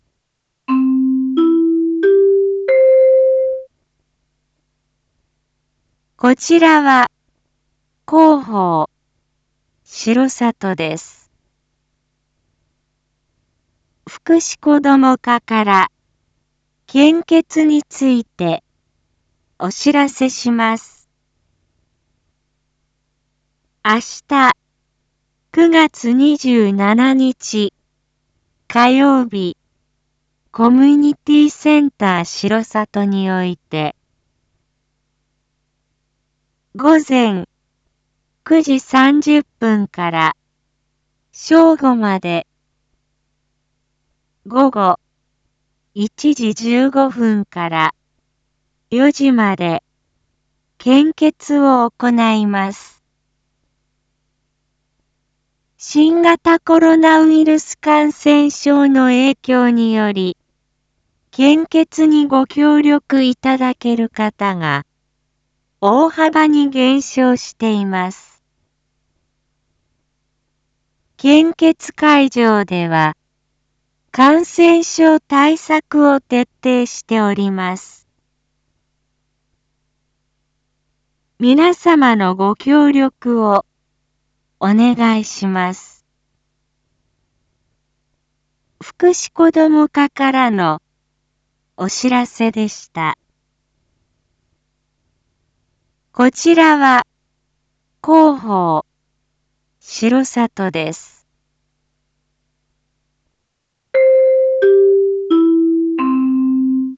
一般放送情報
Back Home 一般放送情報 音声放送 再生 一般放送情報 登録日時：2022-09-26 19:01:48 タイトル：R4.9.26 19時放送分 インフォメーション：こちらは広報しろさとです。 福祉こども課から献血についてお知らせします。